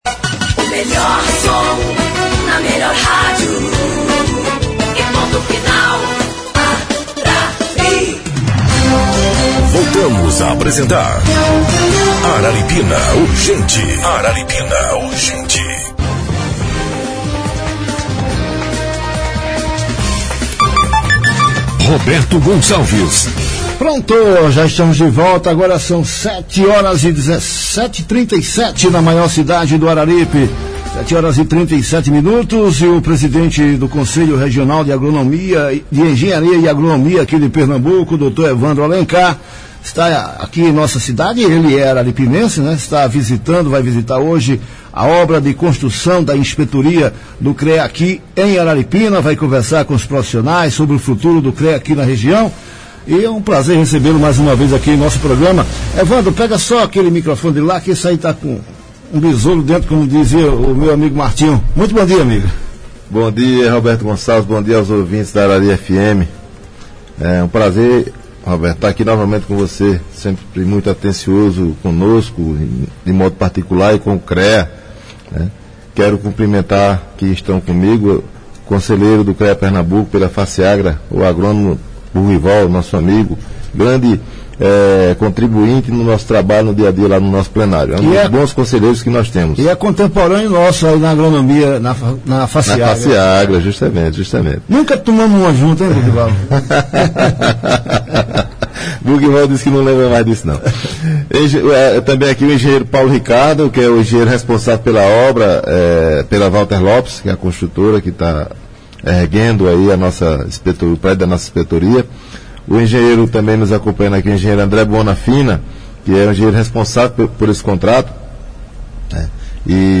A declaração foi dada em entrevista na rádio Arari FM na manhã dessa segunda-feira (17)